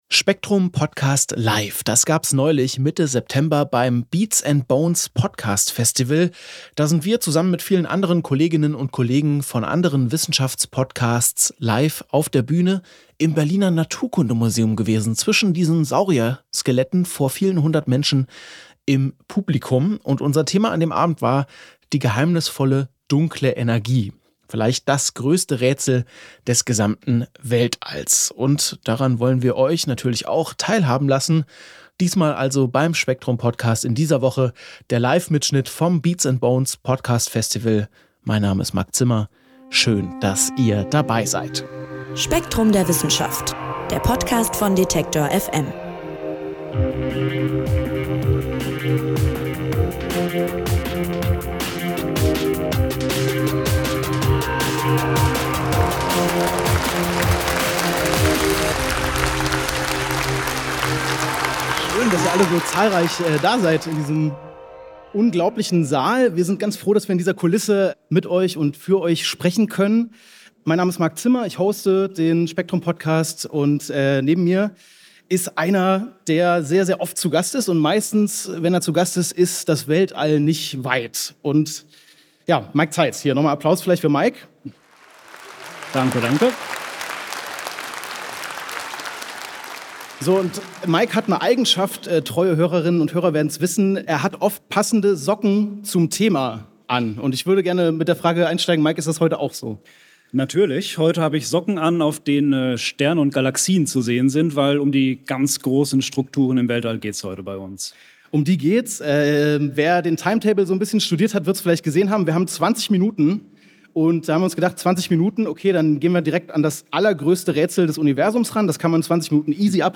spektrumderwissenschaftdunkleenergie-livebeats-and-bones.mp3